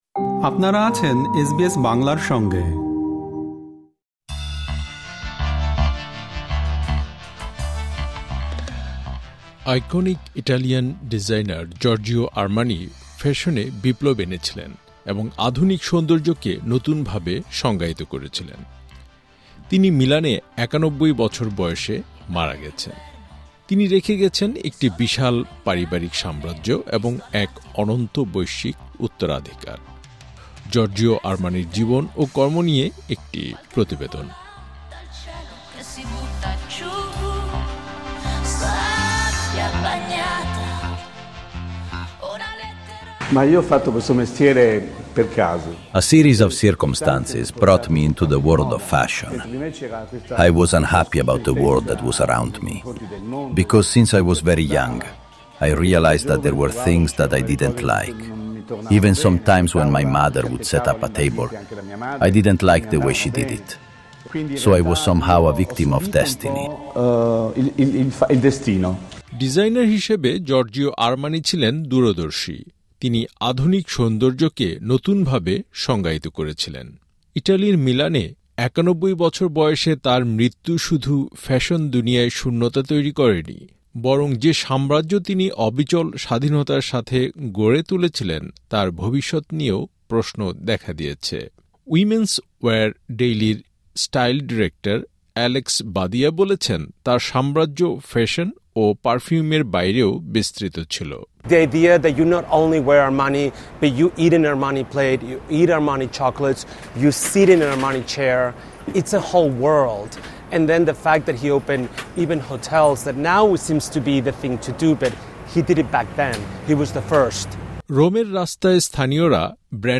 জর্জিও আরমানির জীবন ও কর্ম নিয়ে একটি প্রতিবেদন।